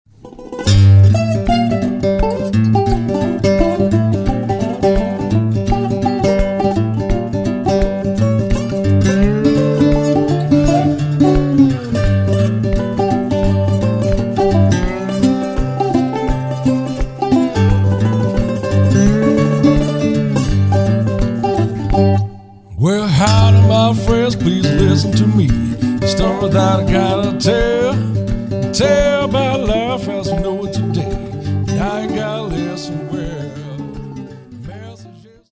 I hope YOU can feel my blues the way I do!!
The old phonograph will play a sample!